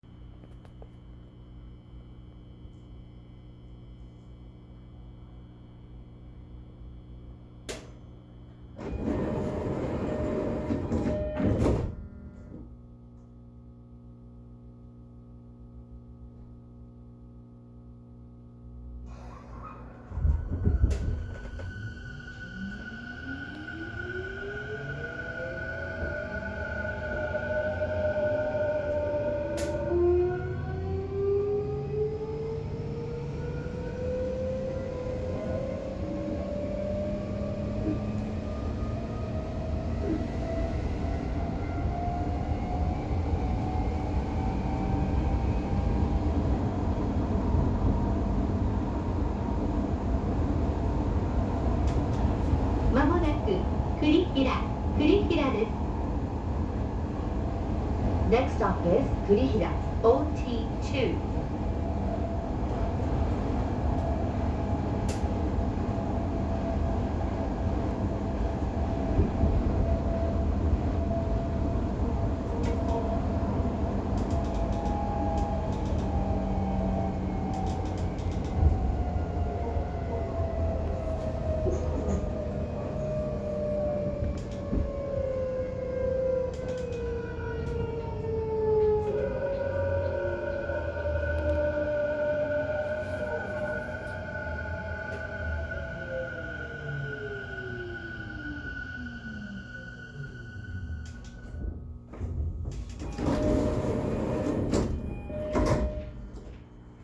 ・4000形走行音
【多摩線】黒川→栗平
起動音こそE233系に似ていますが、一度変調すると全く違う音になります（三菱のIGBTである事自体は同様）。